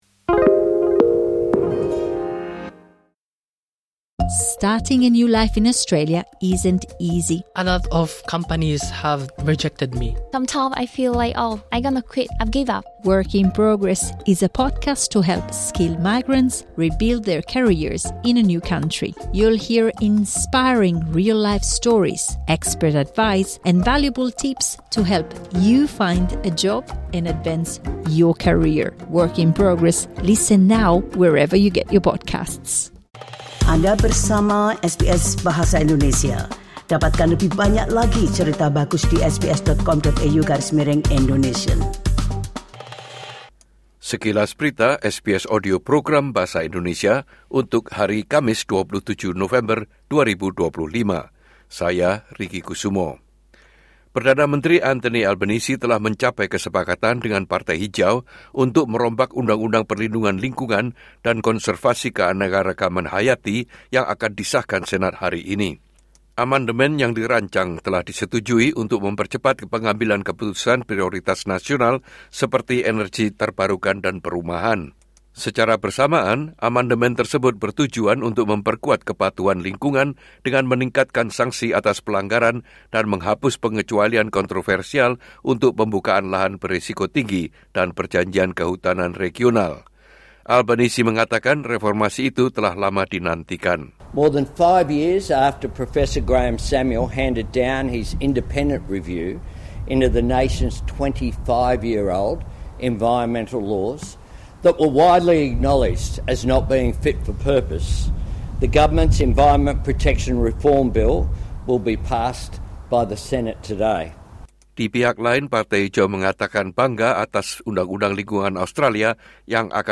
Berita terkini SBS Audio Program Bahasa Indonesia - Kamis 27 November 2025